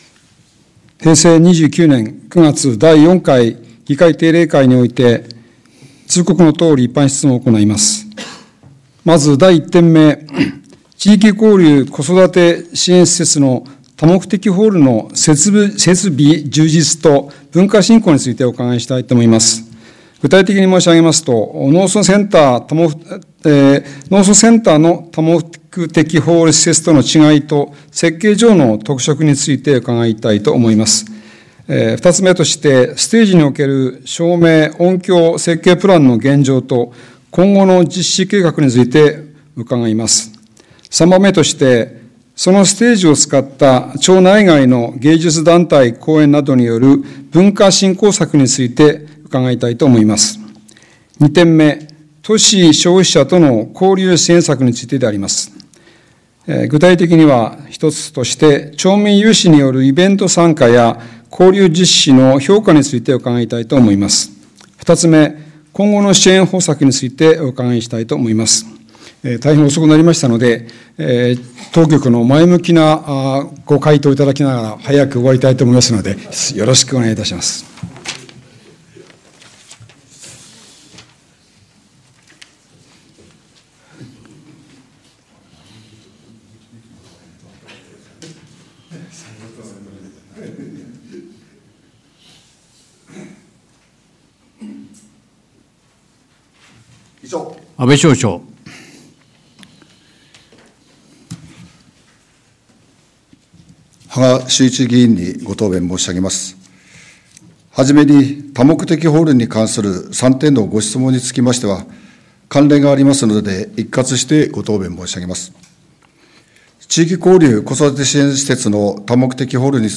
平成29年第4回議会定例会